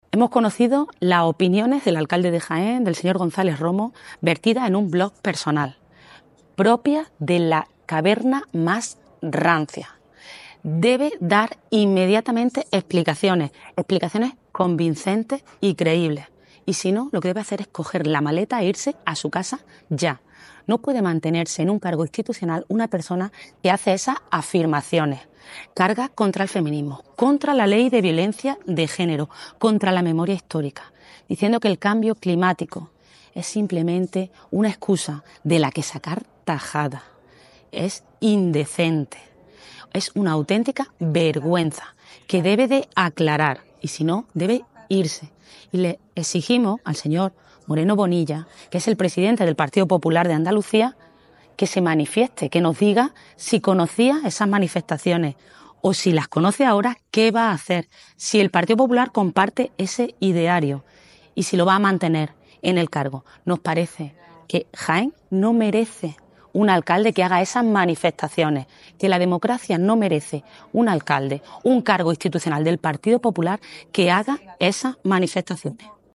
Cortes de sonido
Mercedes-Gamez-sobre-Agustin-Gonzalez.mp3